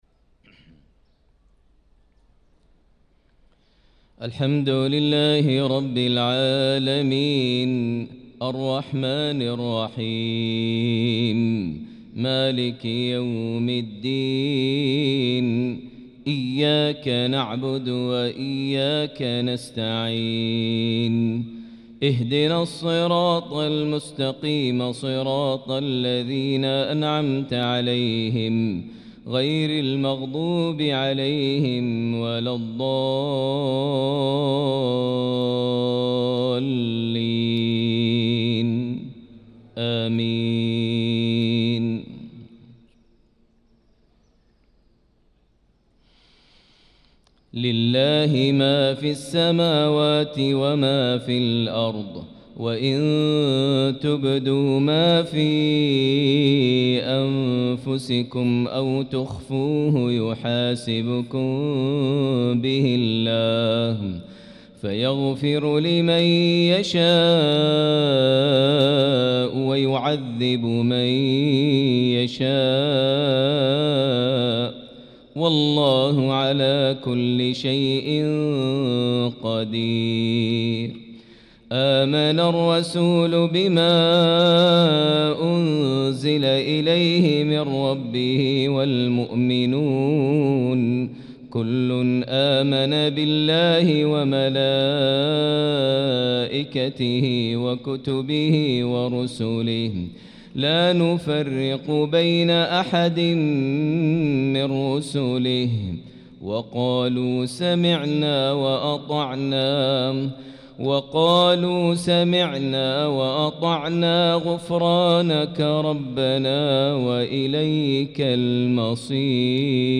صلاة المغرب للقارئ ماهر المعيقلي 5 شوال 1445 هـ
تِلَاوَات الْحَرَمَيْن .